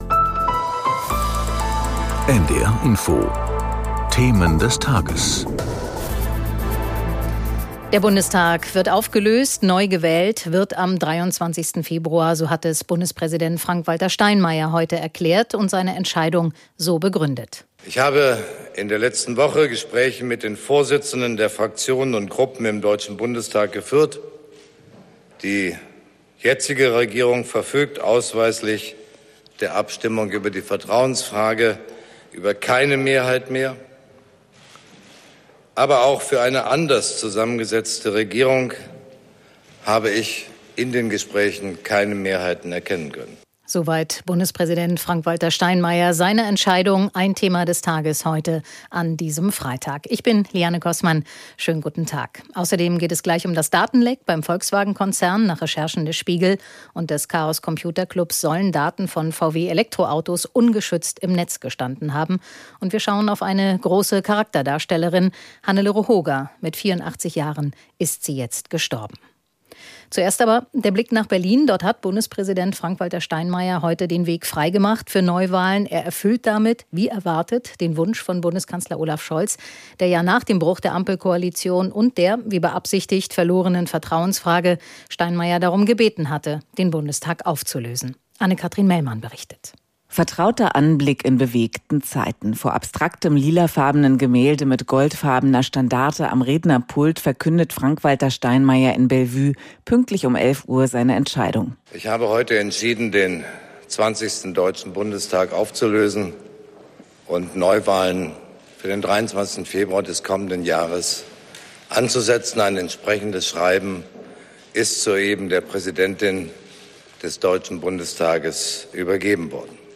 In Gesprächen mit Korrespondenten und Interviews mit Experten oder Politikern.